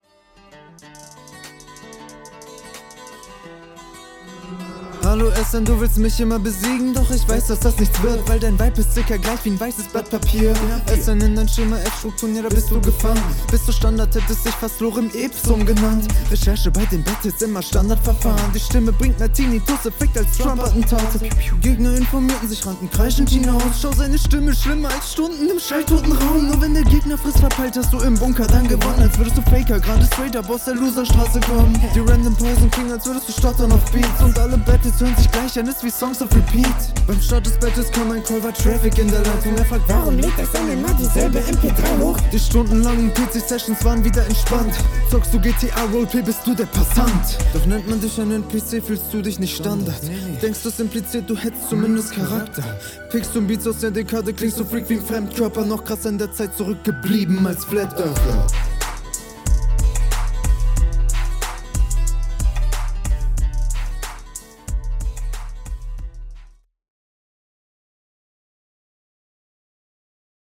Der Mix, oof. Kommt leider nicht so die Stimmung auf.